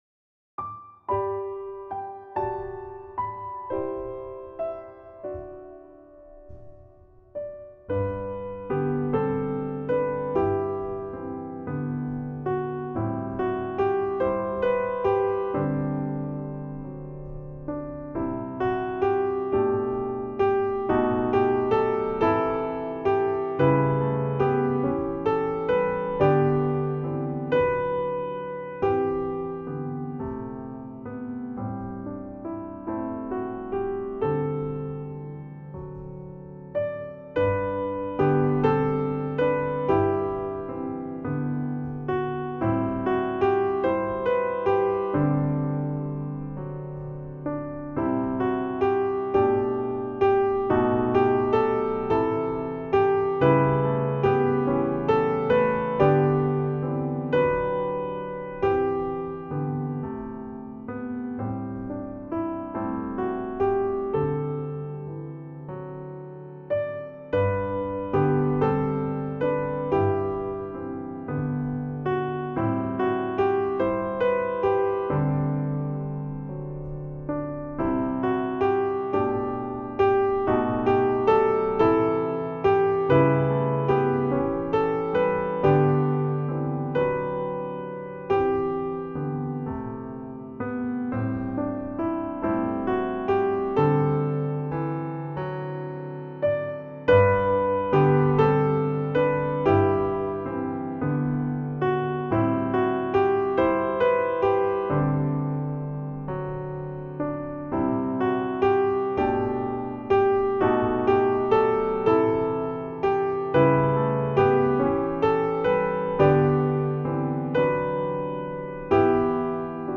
Voicing/Instrumentation: Primary Children/Primary Solo , Vocal Solo